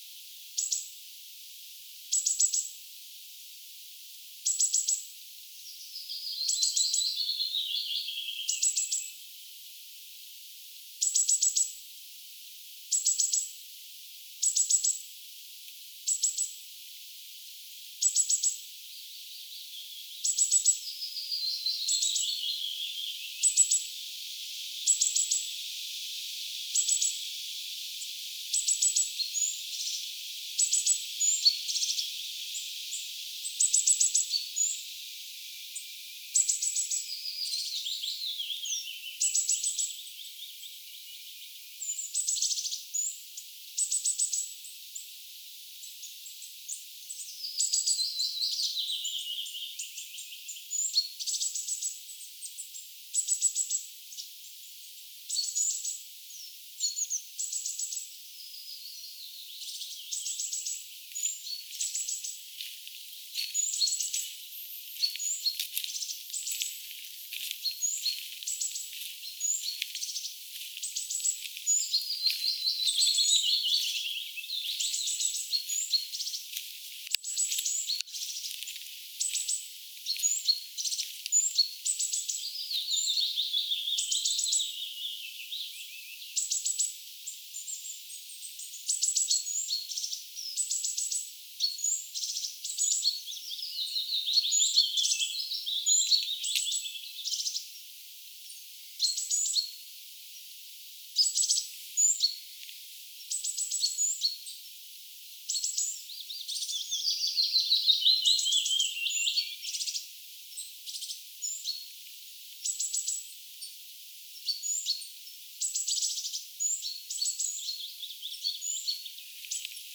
erikoisesti ääntelee kuusitiainen pesänsä vierellä
kuusitiaisen_aantelya_pesansa_lahella.mp3